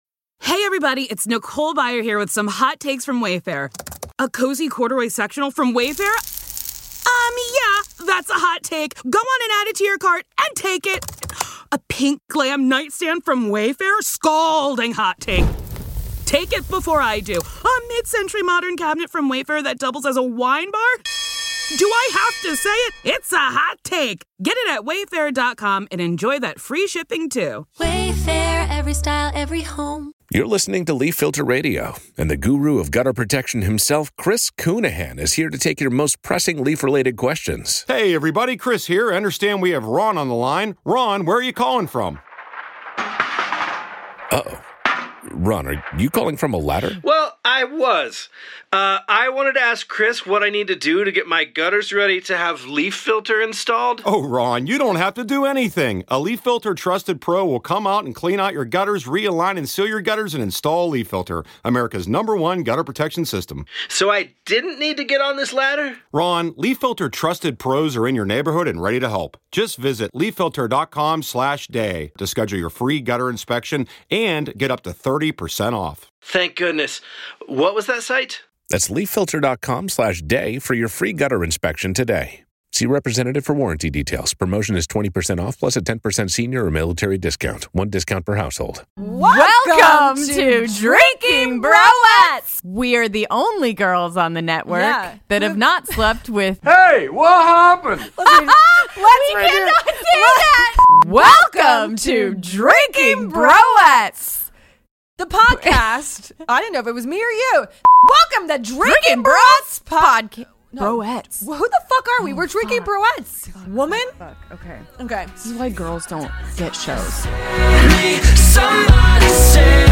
This all female spin-off of the popular Drinkin' Bros Podcast